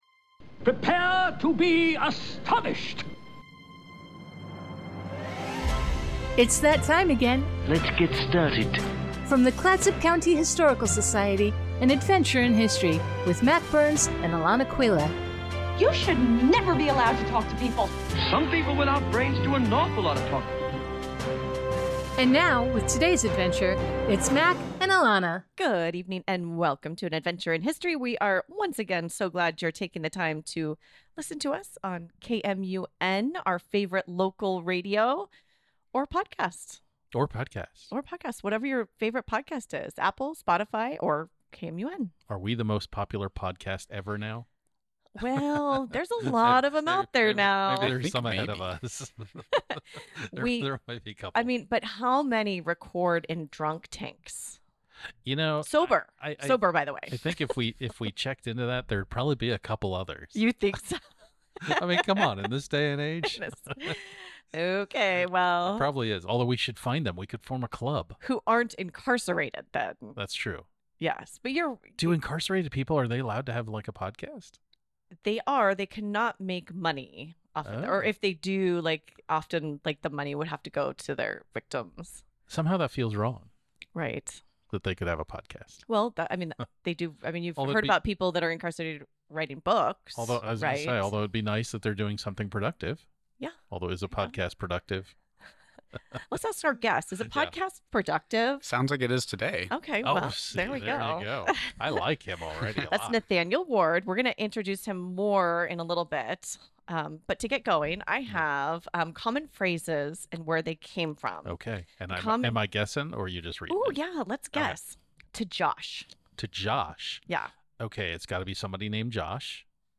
Don’t worry, no one sings.